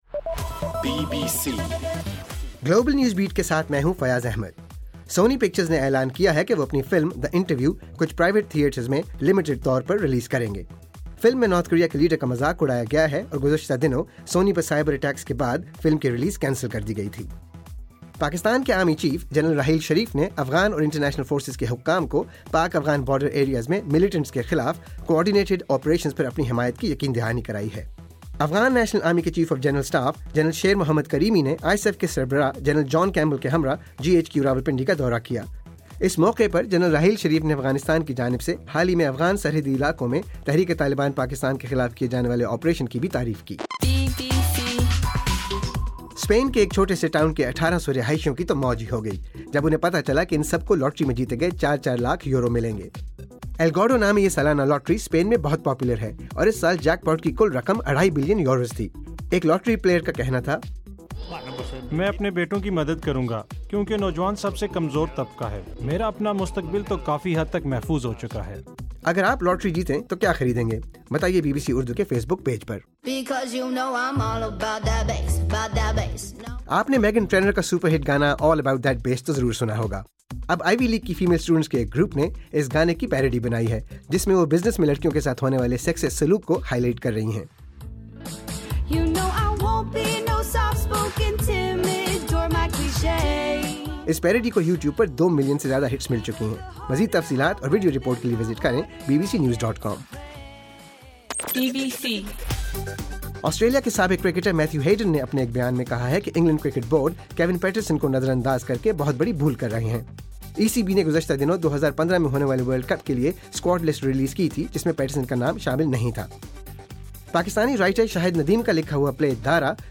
دسمبر 24: صبح 1 بجے کا گلوبل نیوز بیٹ بُلیٹن